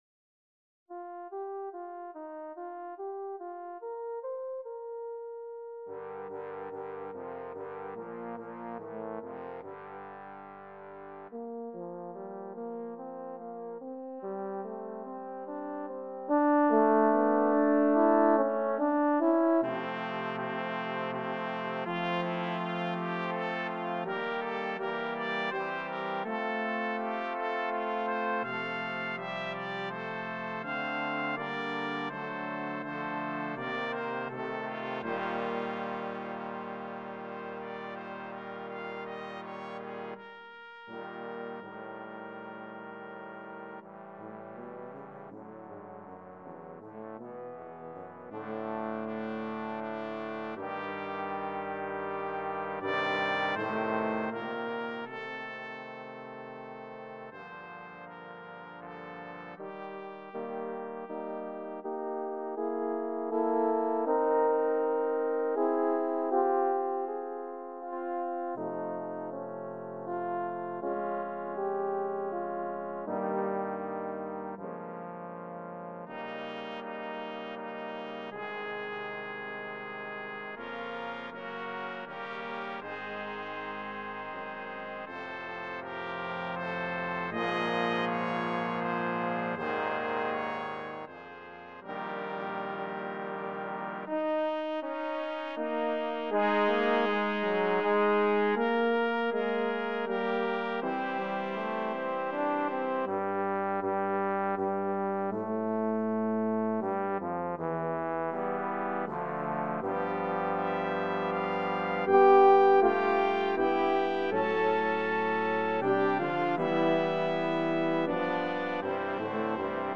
BRASS CHOIR
FOR 3 TRUMPETS, 3 HORNS, 3 TROMBONES, TUBA